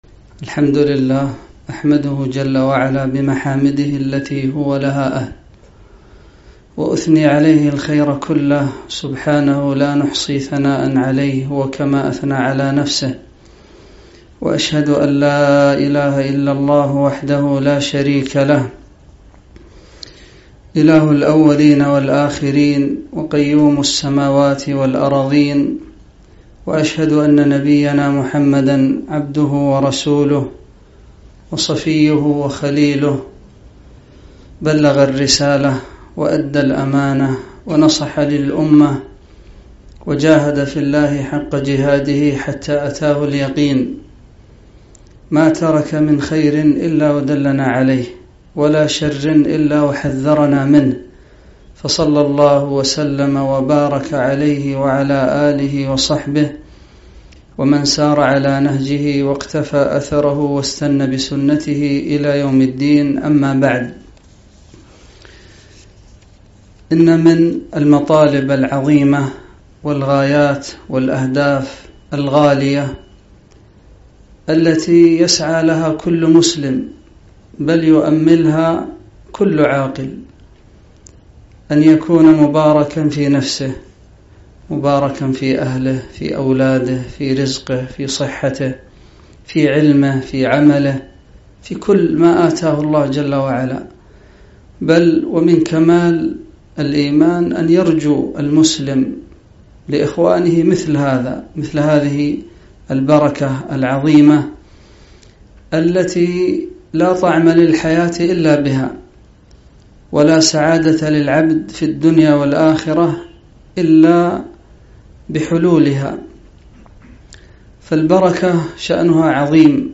محاضرة - مواطن البركة وأسبابها